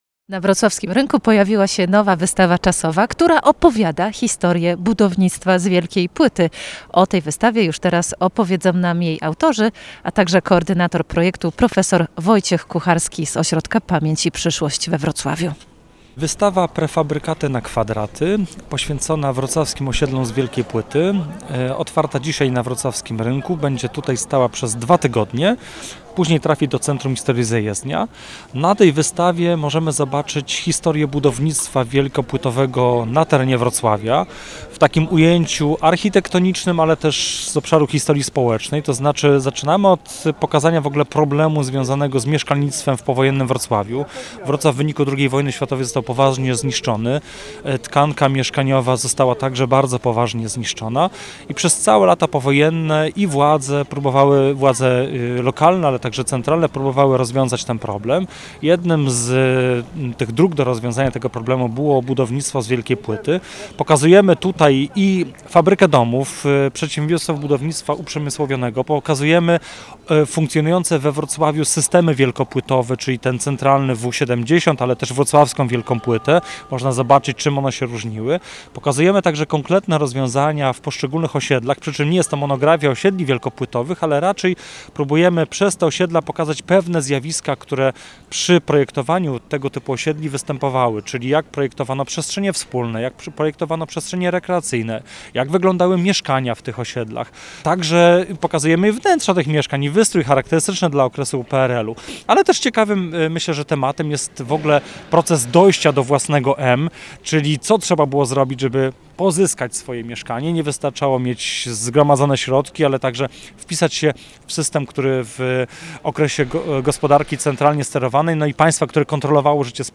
Material-do-puszczenia_Relacja-z-otwarcia-wystawy-historycznej.mp3